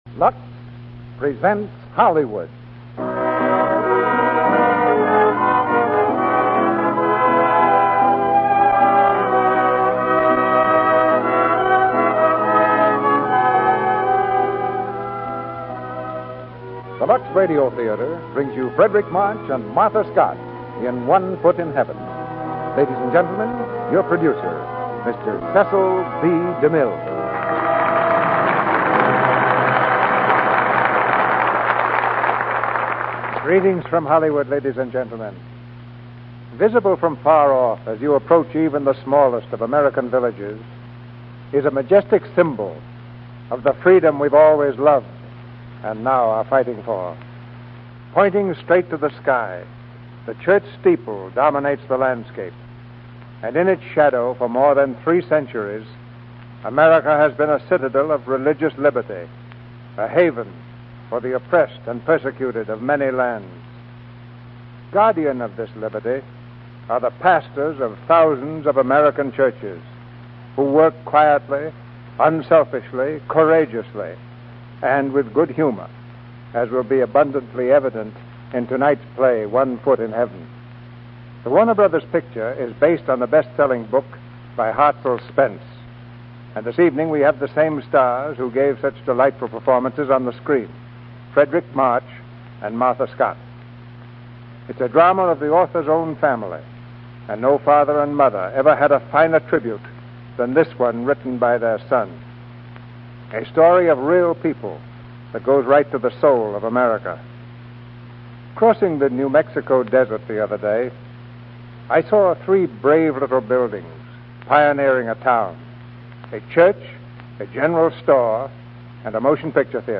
starring Frederic March, Martha Scott, Verna Felton
Lux Radio Theater Radio Show